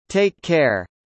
take care（テイッケア）
takeのｋが発音されない